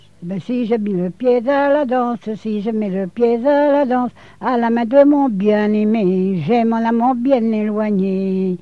danse : ronde
Genre strophique
Pièce musicale inédite